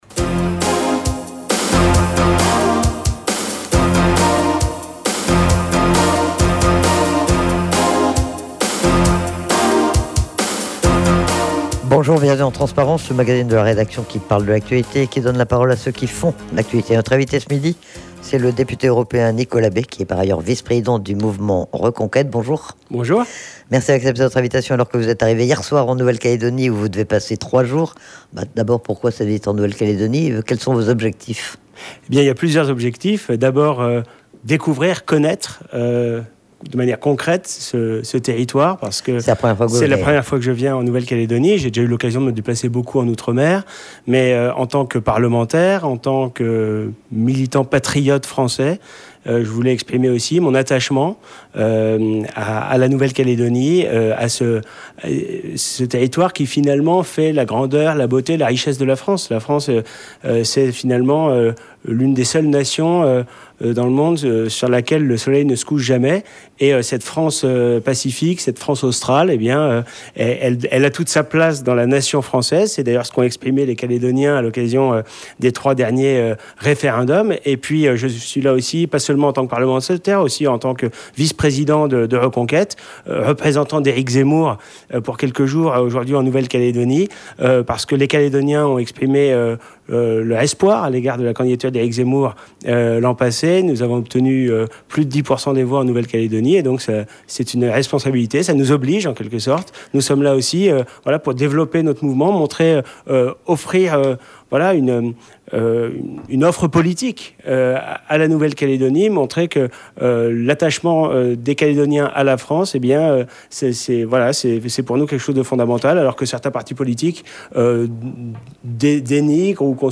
Il est interrogé sur l'actualité nationale et internationale, sur son mandat de député européen et sur la situation de Reconquête. L'occasion également de savoir quelle est la position du parti d'Éric Zemmour sur le dossier calédonien.